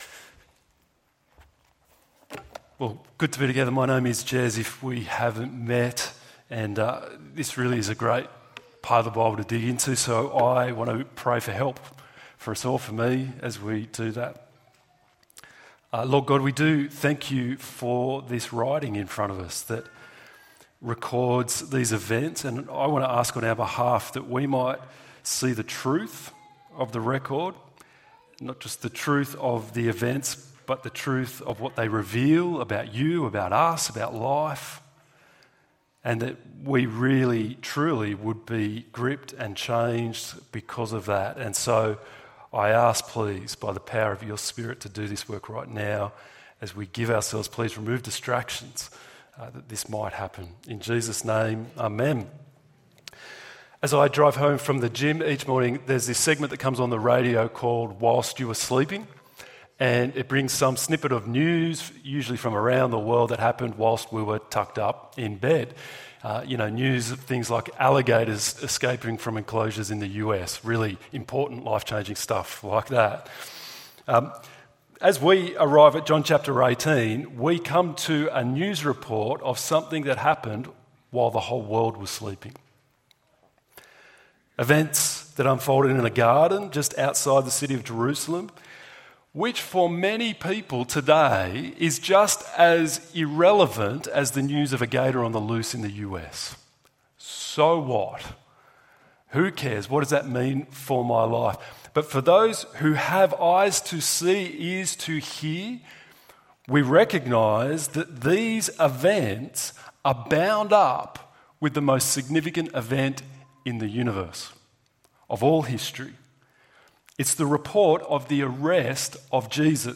Shall I not drink the cup? ~ EV Church Sermons Podcast